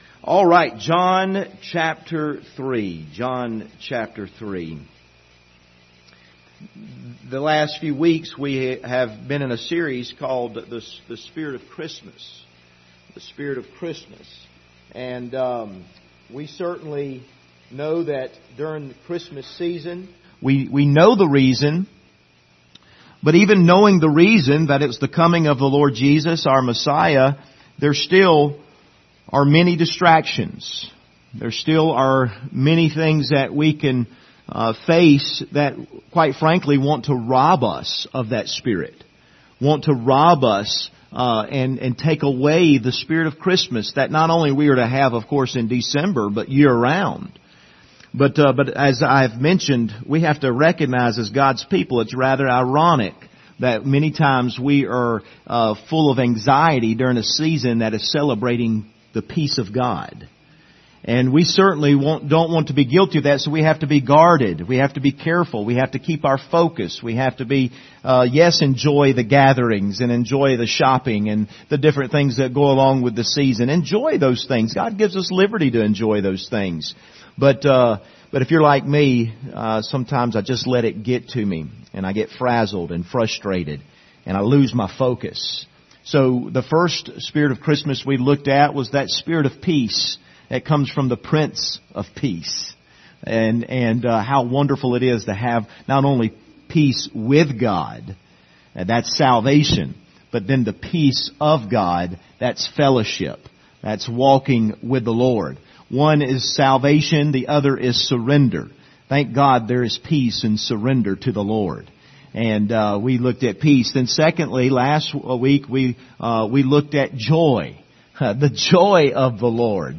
Passage: John 3:11-13 Service Type: Sunday Morning